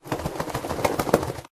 whine.ogg